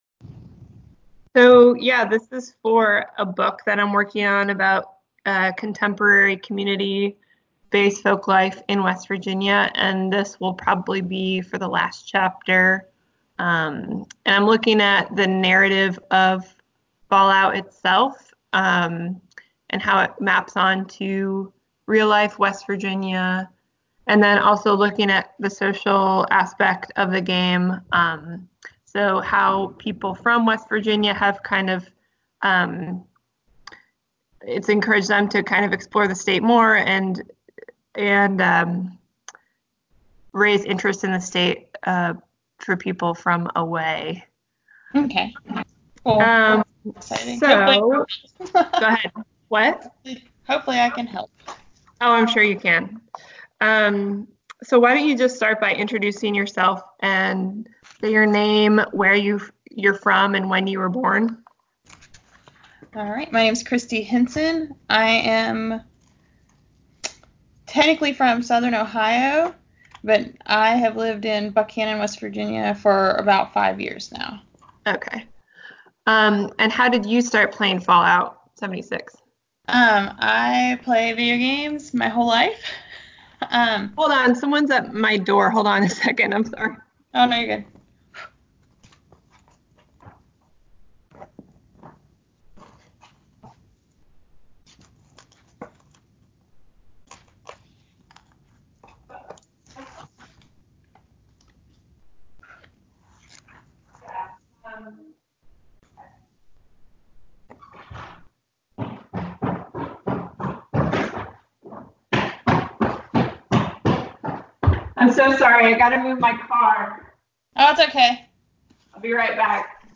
This interview is part of a series of interviews conducted with Fallout 76 gamers, some of whom are from West Virginia, and some of whom were inspired to visit West Virginia because of the game.